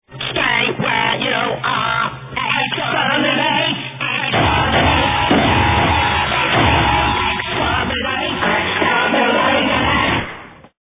dalekchorus2.mp3